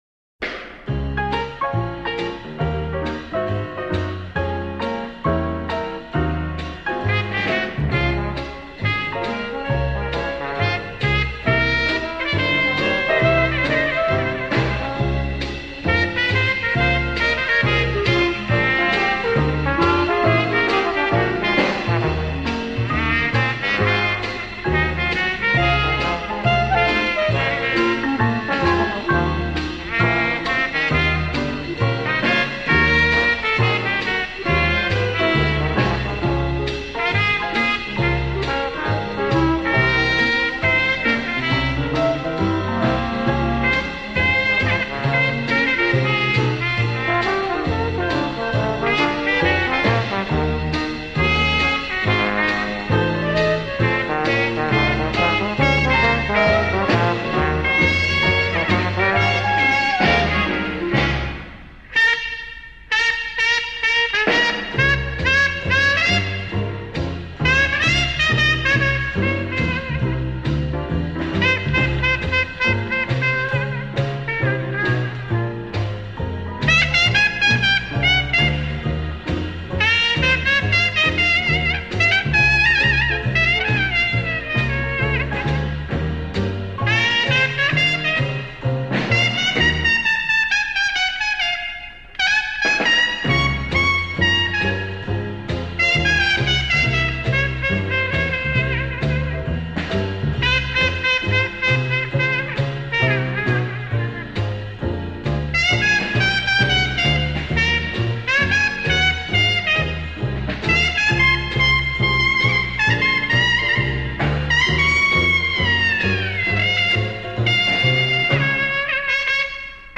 古典爵士